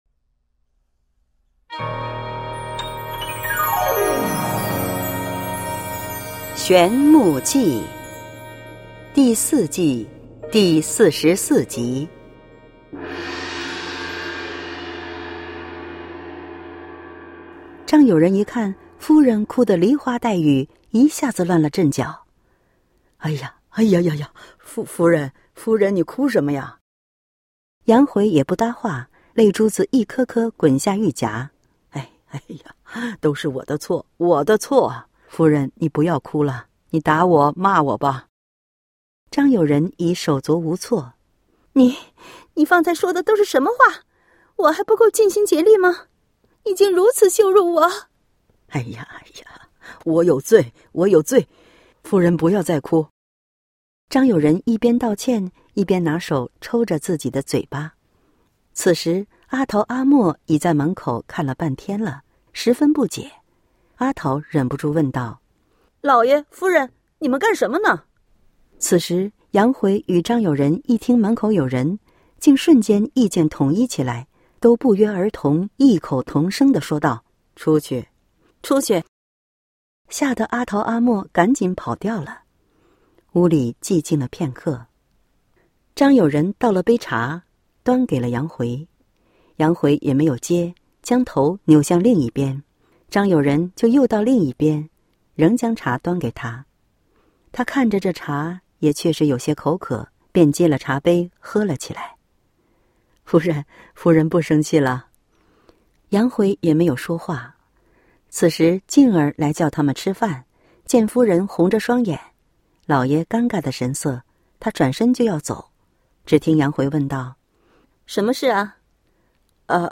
小說連播（音頻）：玄木記 第四季 (41-50） | 法輪大法正見網